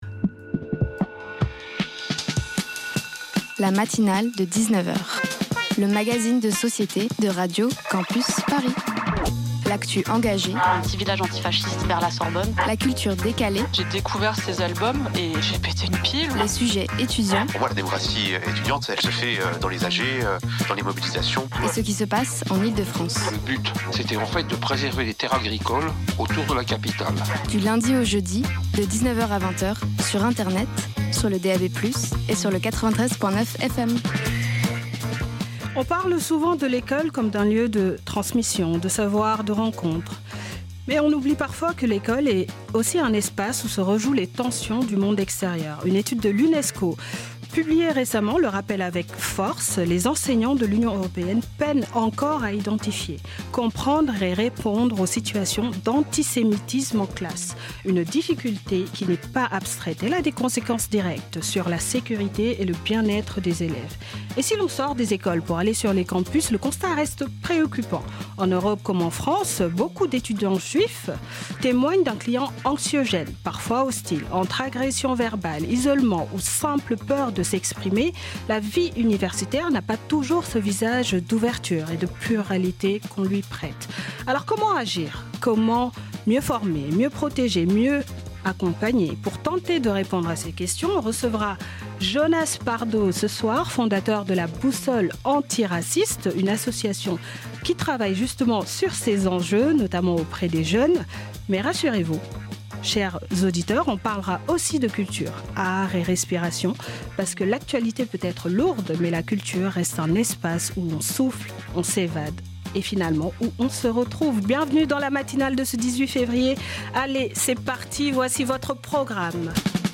Former à la lutte contre l'antisémitisme & l'exposition street art Zoo Art Show Partager Type Magazine Société Culture mercredi 18 février 2026 Lire Pause Télécharger Ce soir